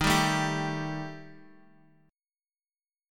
EbMb5 chord